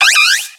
Cri de Vipélierre dans Pokémon X et Y.